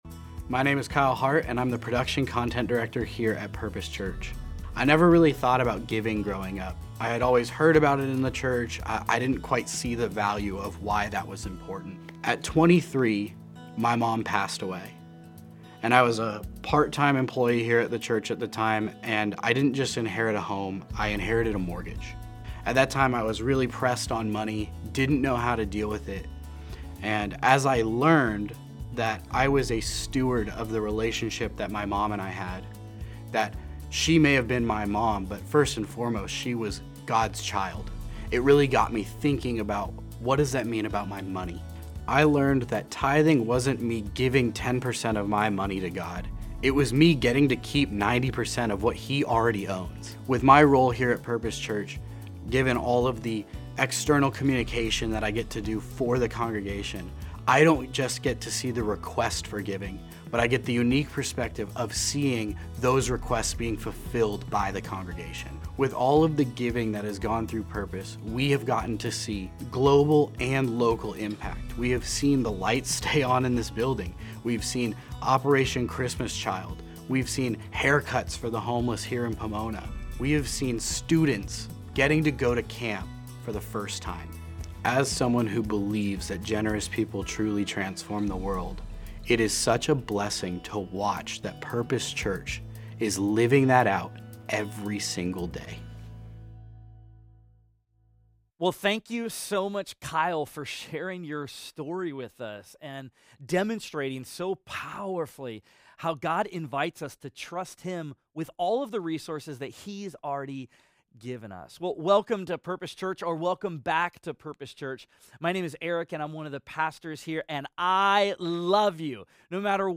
Study Guide | Download Audio File Traditional Worship (In-Person Service)